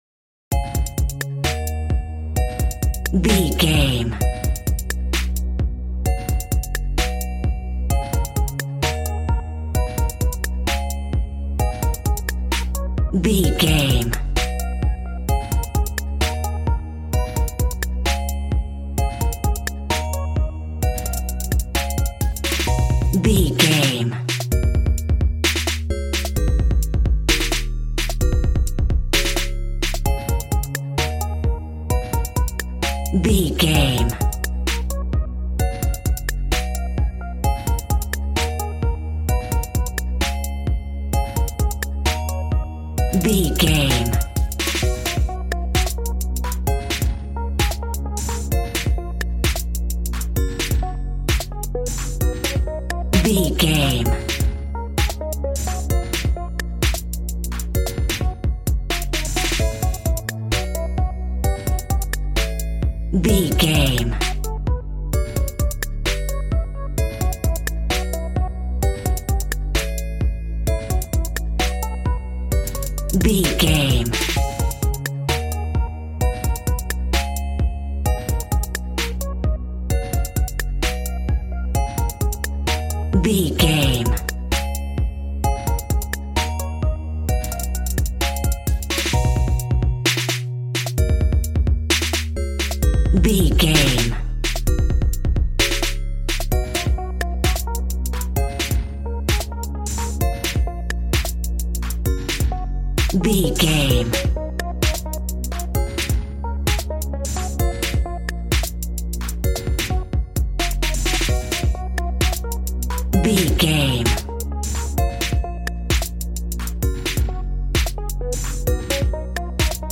Aeolian/Minor
A♭
groovy
synthesiser
drums
cool
piano